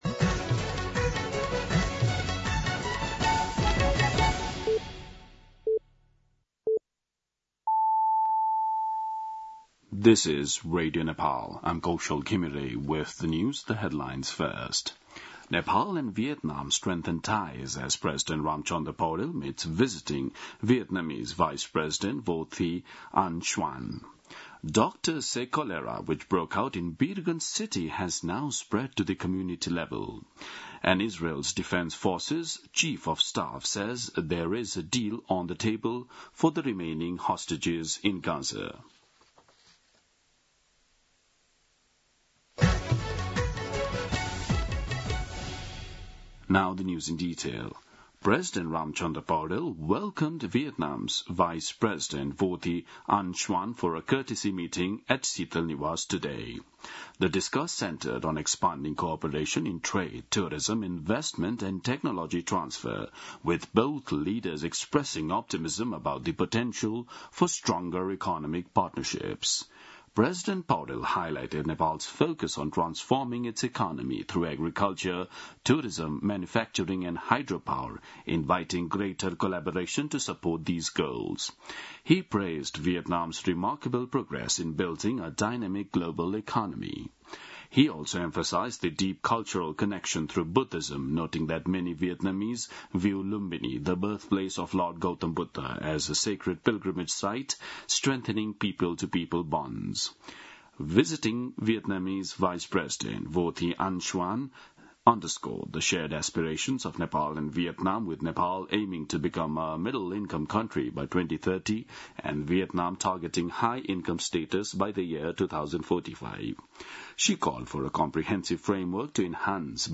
दिउँसो २ बजेको अङ्ग्रेजी समाचार : ९ भदौ , २०८२
2-pm-English-News-09.mp3